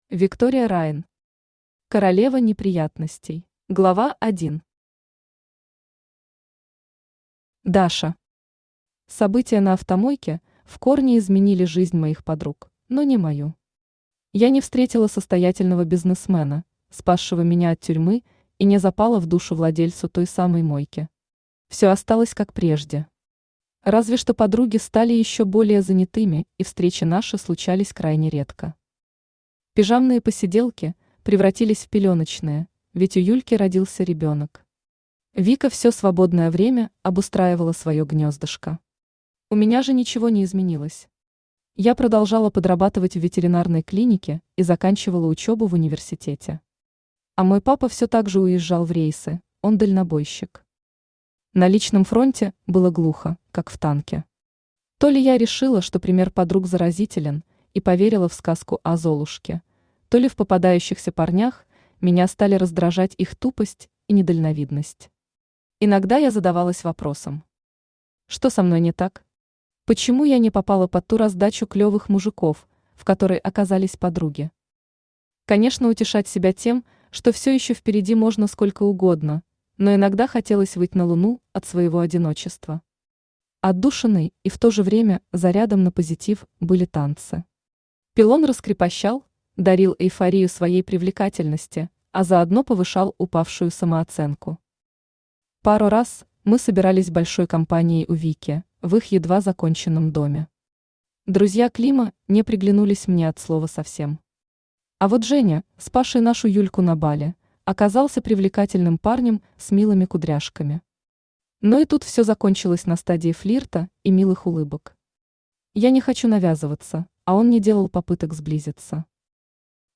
Аудиокнига Королева неприятностей | Библиотека аудиокниг
Aудиокнига Королева неприятностей Автор Виктория Райн Читает аудиокнигу Авточтец ЛитРес.